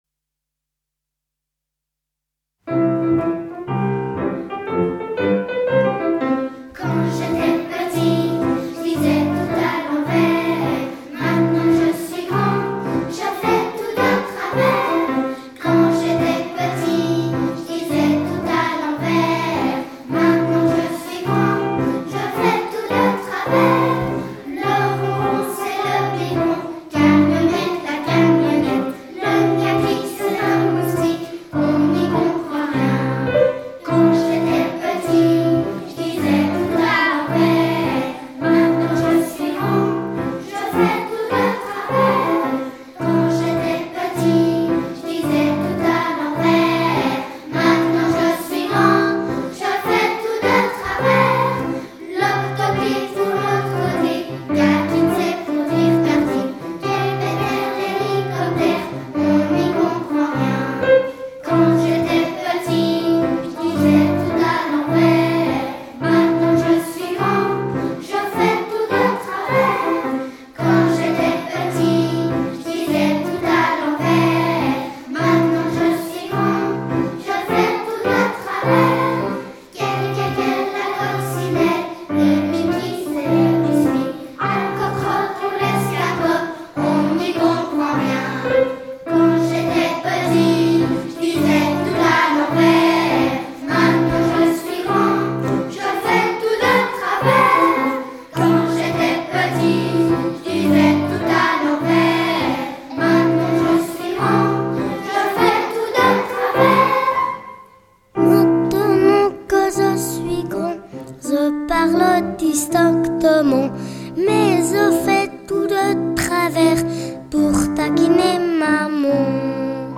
2012 - 2013 - Choeur d'enfants La Voix du Gibloux
Concert de Noël avec Anonymos à Riaz
Nous avons eu la chance de chanter dans une église à l’acoustique généreuse.